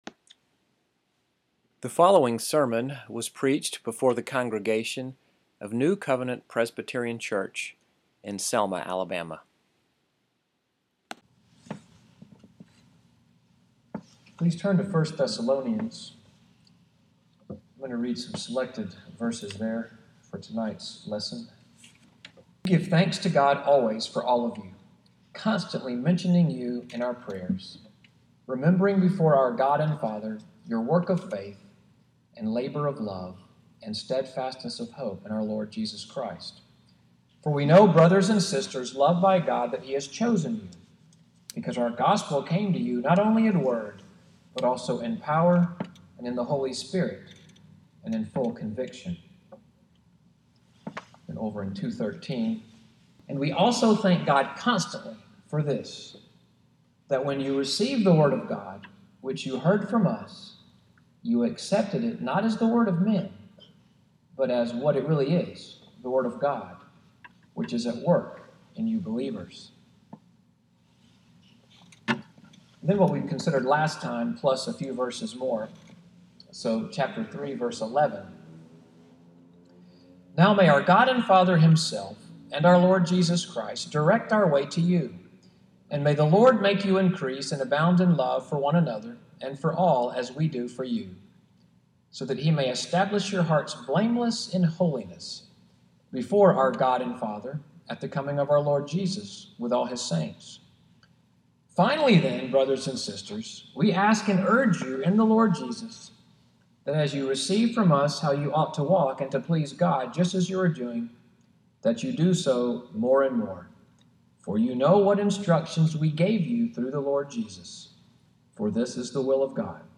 EVENING WORSHIP at NCPC-Selma, sermon audio from, “Belief & Behavior,” September 10, 2017.